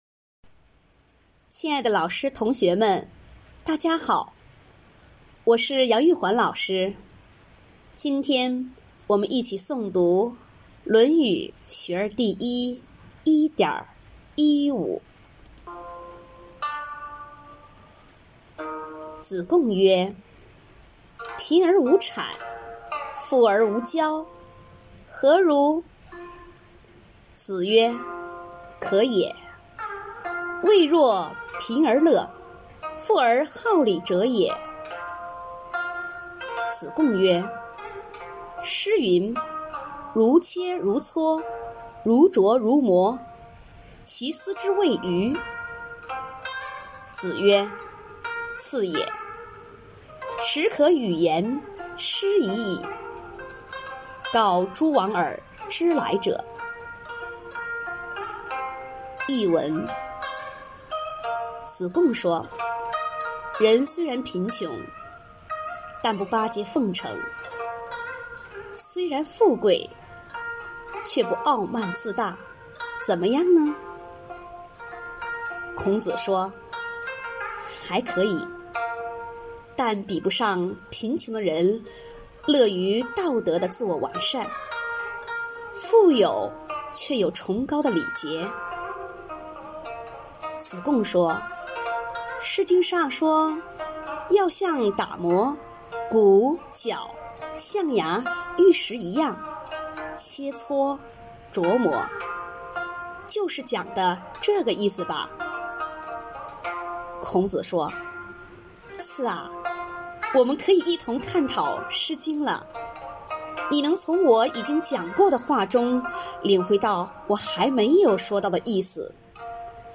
每日一诵0306.mp3